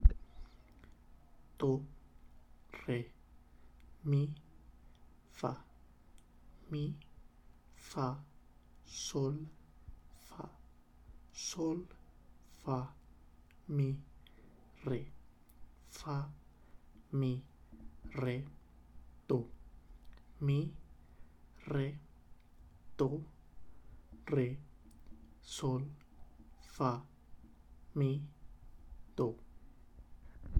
All you have to do is read the name of the correct syllable from a note in a score as if you were reading a book, in neutral tone, respecting the right rhythm.
Exercise 1 – Spoken